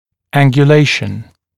[ˌæŋgju’leɪʃən][ˌэнгйу’лэйшэн]ангуляция, наклон, степень наклона